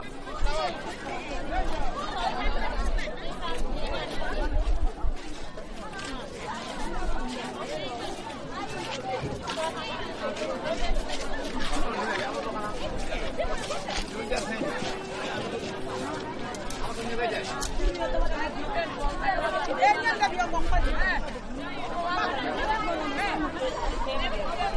Au village les pêcheurs sont revenus et vendent le poisson sous la halle, brouhaha des conversations et marchandages je regrette d’avoir oublié de prendre l’enregistreur au campement.
Bruits au marché de Lompoul :